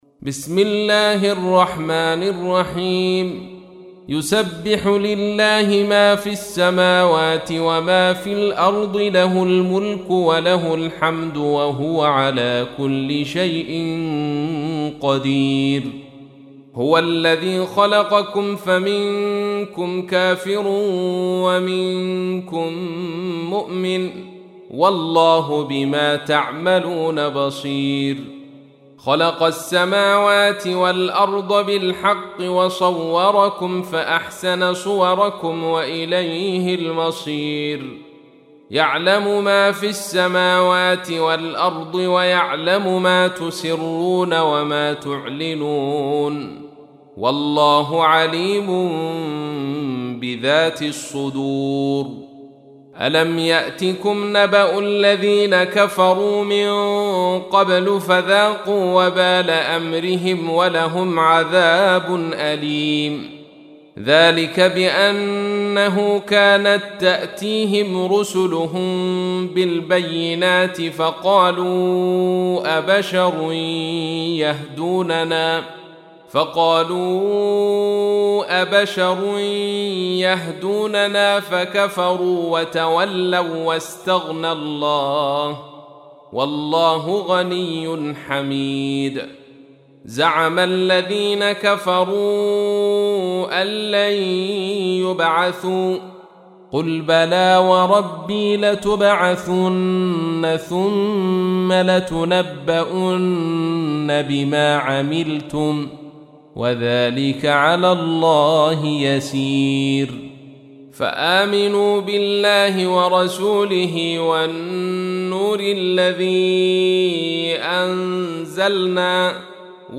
64. سورة التغابن / القارئ